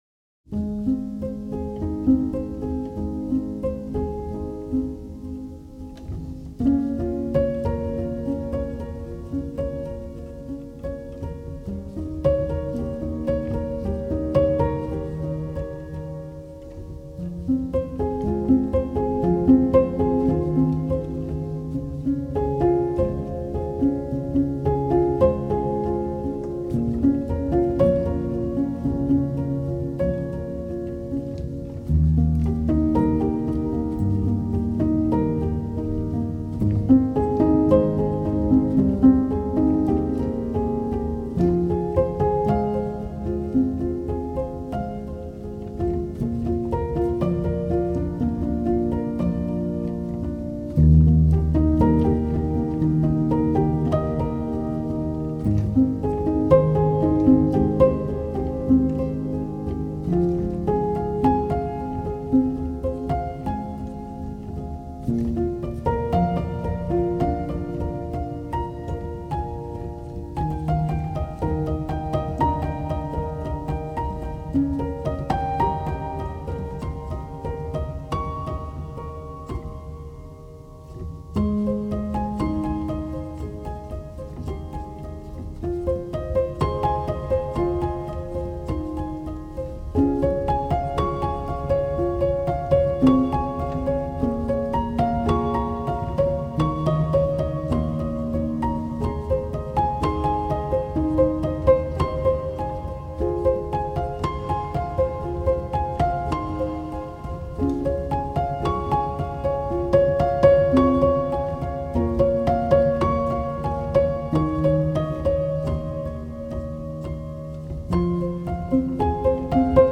موسیقی کنار تو
سبک آرامش بخش , پیانو , عاشقانه , عصر جدید , موسیقی بی کلام